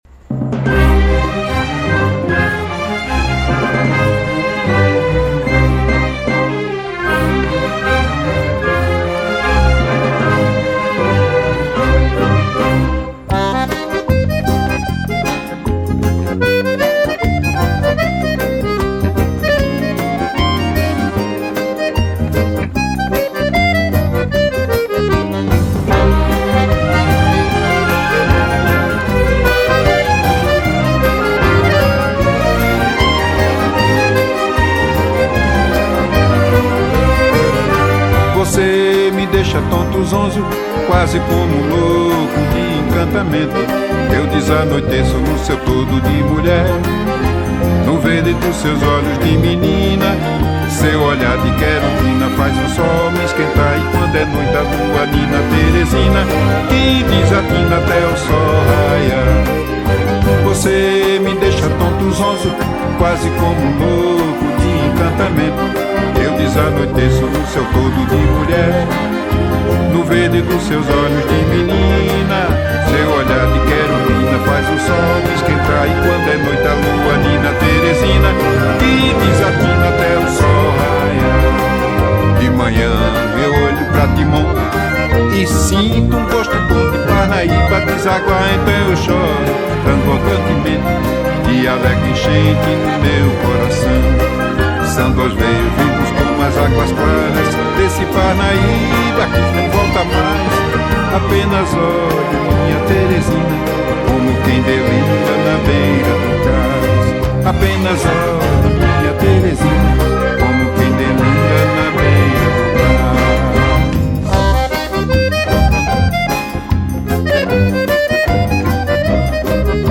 06:17:00   Xote